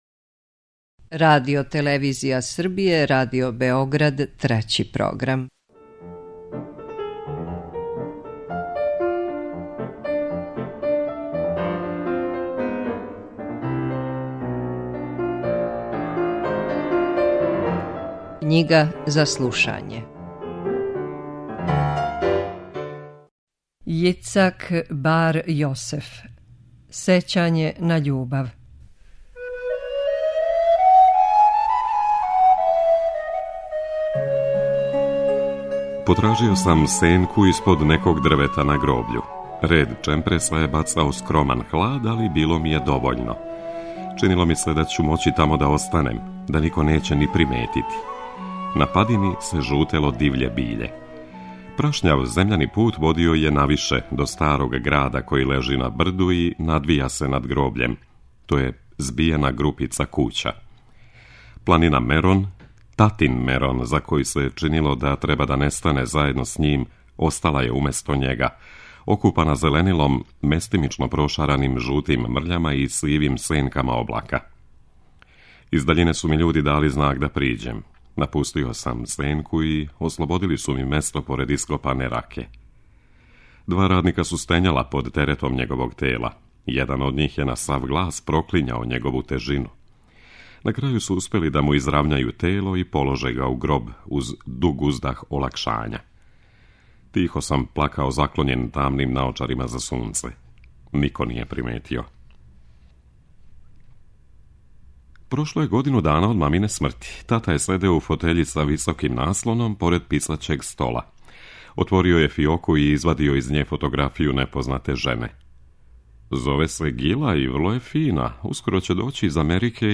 У емисији КЊИГА ЗА СЛУШАЊЕ, коју Трећи програм реализује у сарадњи са издавачком кућом Клио, током октобра можете пратити роман „Сећање на љубав”, израелског писца Јицака Бар Јосефа.